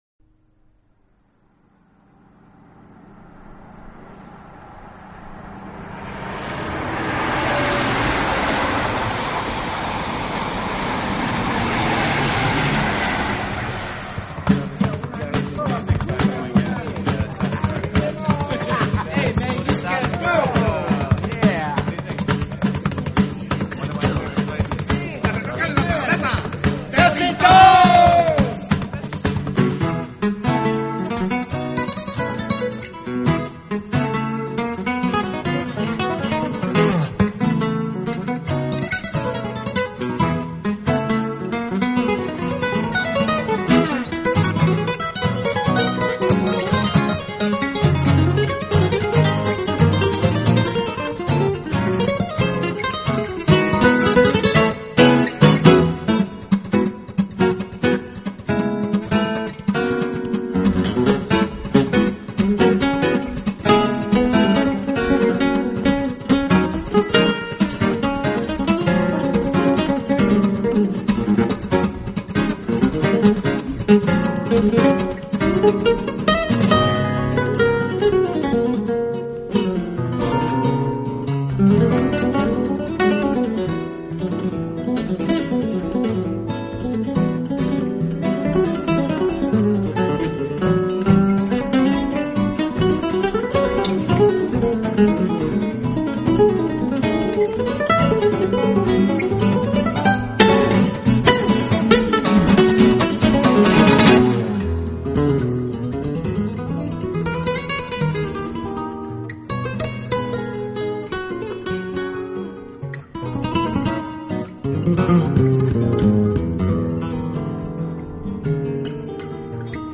风格：Jazz
抑或是独奏，无论从音乐内容还是音响效果来说，是三重奏弦乐无与伦比的天籁之音！
弹奏出来的音色丰满，
低音非常有力；